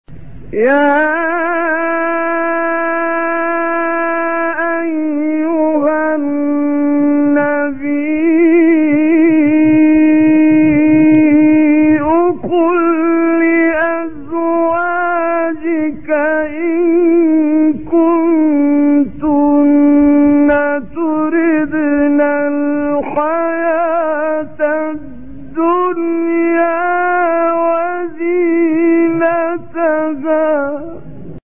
9 فراز از «کامل یوسف» در مقام صبا
به گزارش خبرگزاری بین‌‌المللی قرآن(ایکنا) 9 مقطع صوتی از کامل یوسف البهتیمی، قاری برجسته مصری که در مقام صبا اجرا شده، در کانال تلگرامی کامل یوسف البهتیمی منتشر شده است، در زیر ارائه می‌شود.
برچسب ها: خبرگزاری قرآن ، ایکنا ، شبکه اجتماعی ، کامل یوسف البهتیمی ، قاری مصری ، مقام صبا ، فراز صبا ، تلاوت قرآن ، مقطعی از تلاوت ، قرآن ، iqna